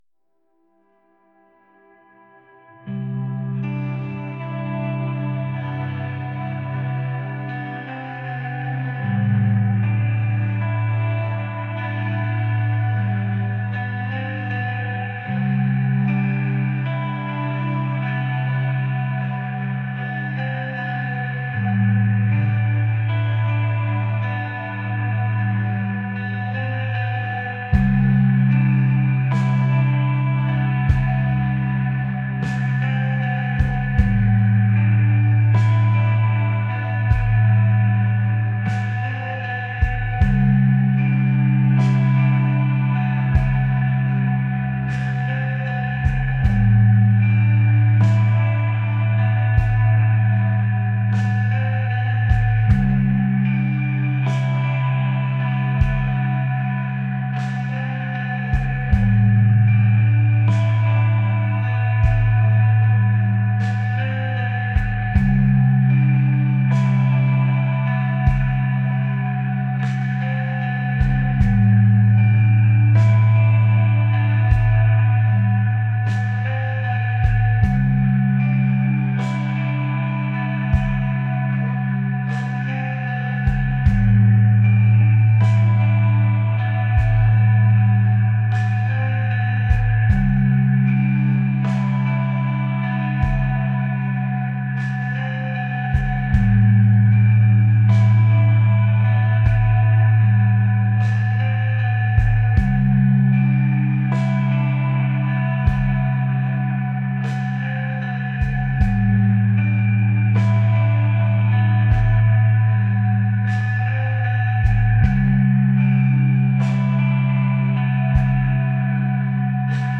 atmospheric | dreamy